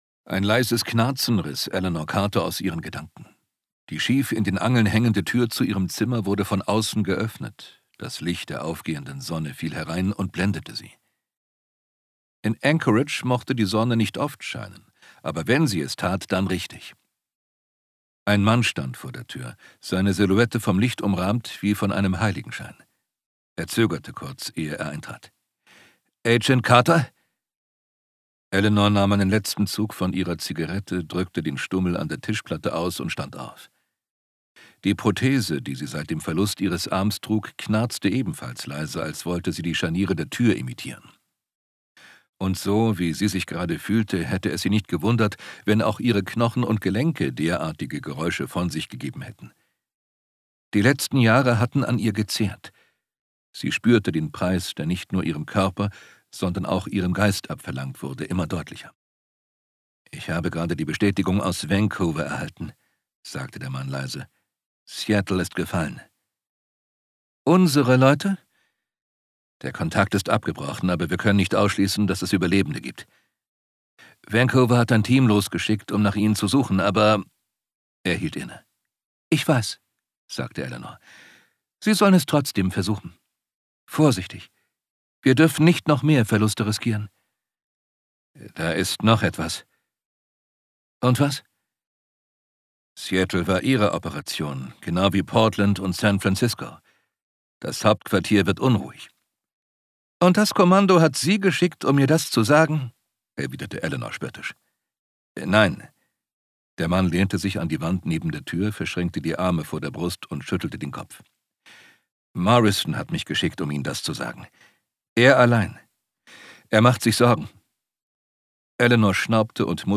Fantasy und Science Fiction Hörbücher
Ungekürzte Lesung